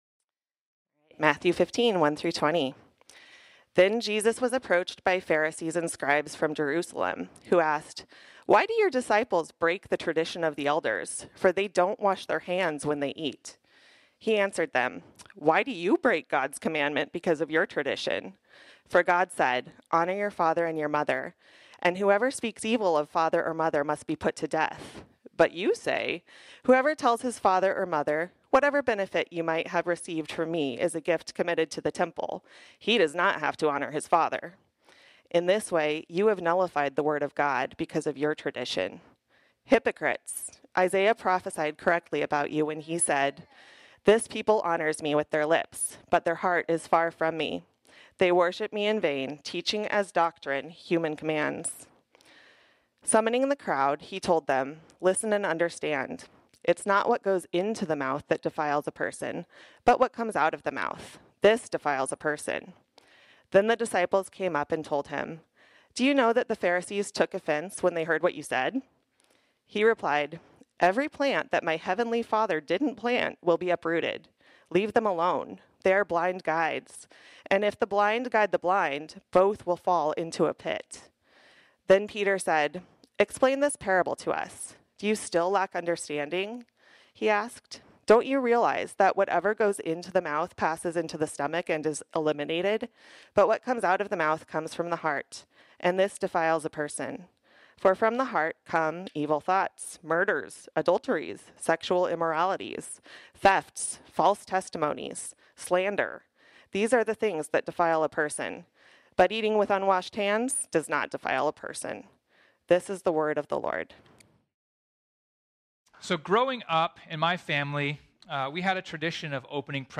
This sermon was originally preached on Sunday, July 7, 2024.